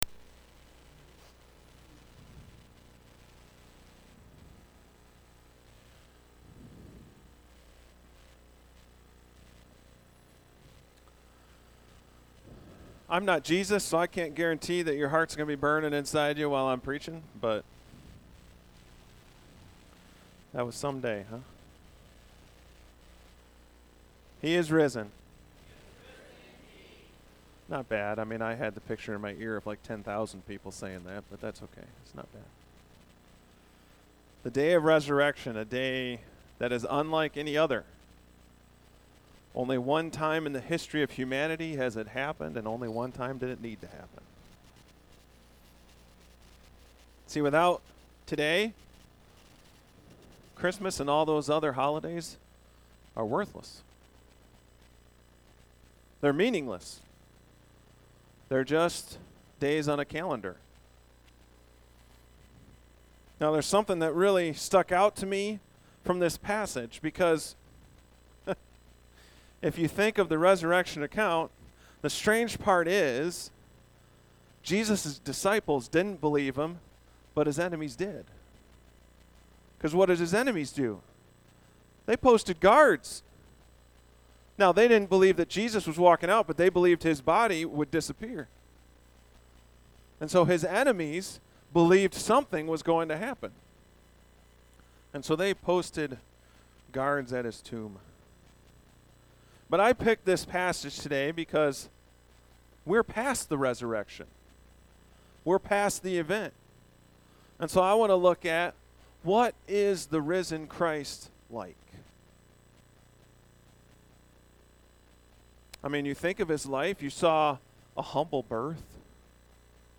Message
Resurrection Sunday message